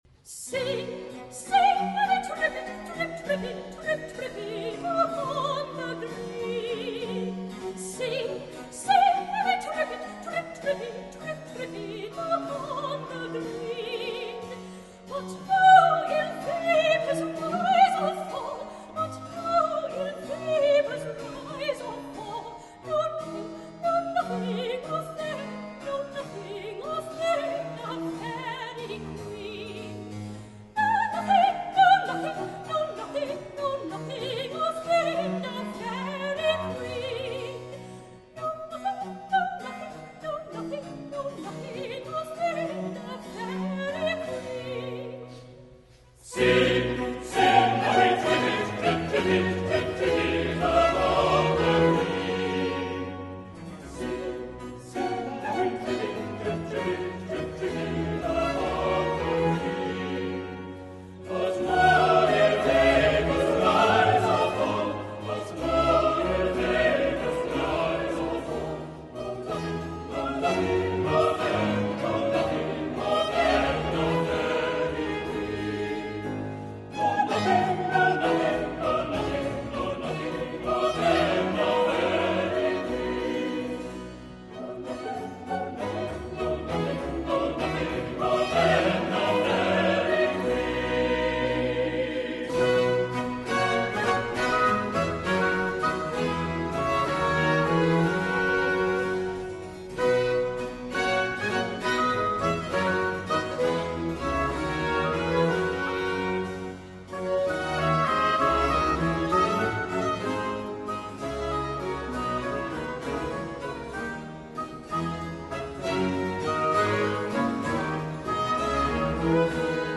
14 - The Fairy Queen, semi-opera, Z. 629- Act 2.
Solo with Chorus.